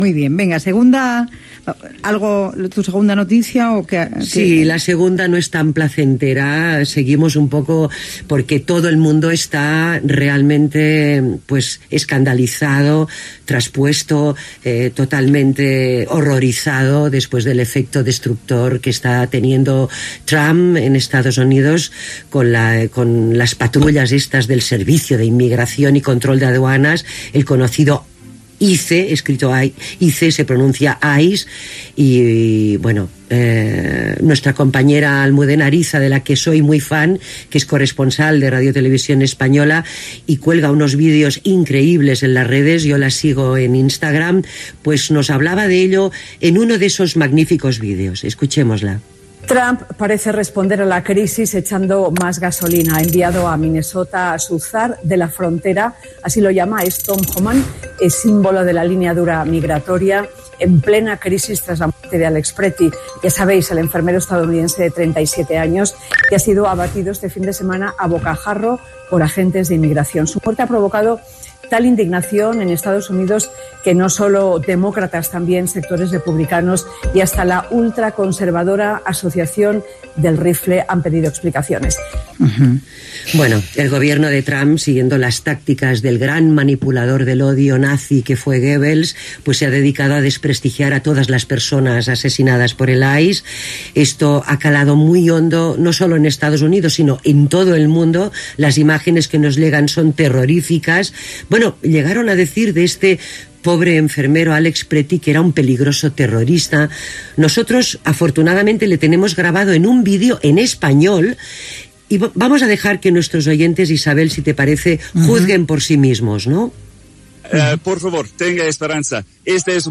Comentaris sobre la intervenció del Servei de Control de Duanes (ICE) a Estats Units. Reproducció d'una crònica d'Almudena Ariza a TVE.
Entreteniment
FM